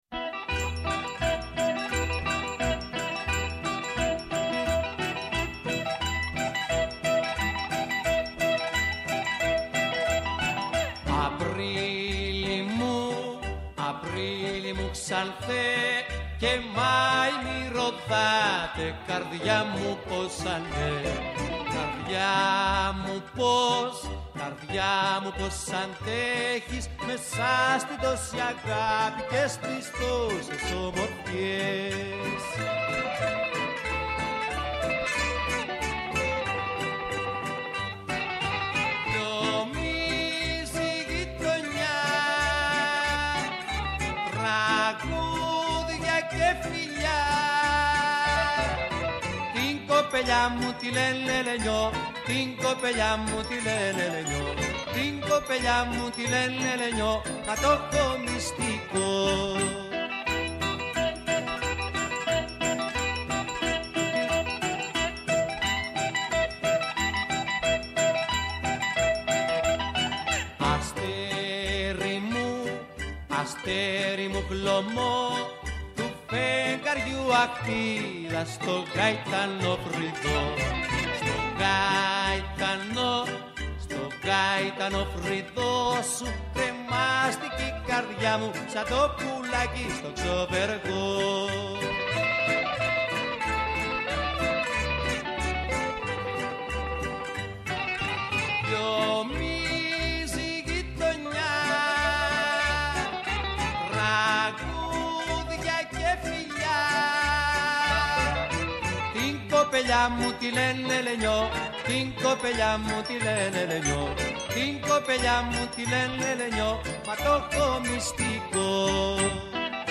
Μια εκπομπή για το ντοκιμαντέρ και τους δημιουργούς του στο Α’ Πρόγραμμα της ΕΡΤ κάθε Σάββατο λίγο μετά τις 8 το βράδυ.
Το Σάββατο 1 Απριλίου ,στην εκπομπή DOC on Air που αγαπά το ντοκιμαντέρ και δίνει βήμα στους δημιουργούς του,καλεσμένες θα βρίσκονται: